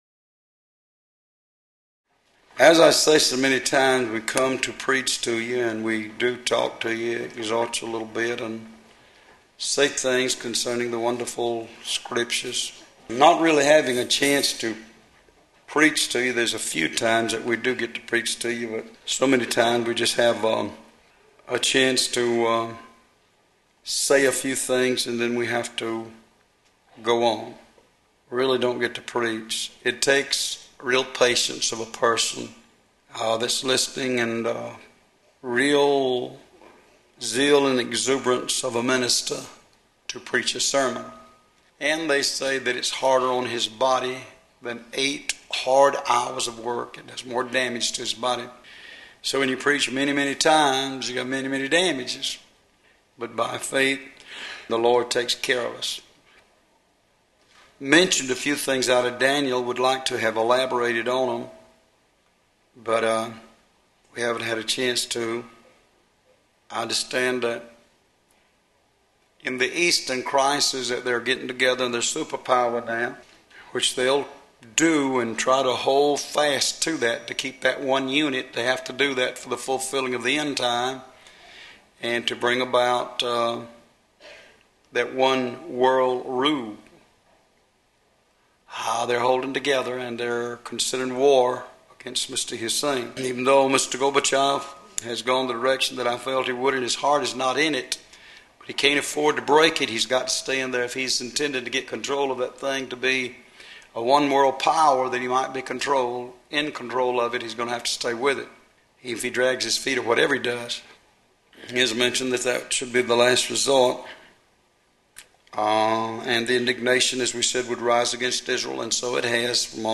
Location: Love’s Temple in Monroe, GA USA
Sermons